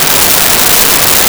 Static Loop 01
Static Loop 01.wav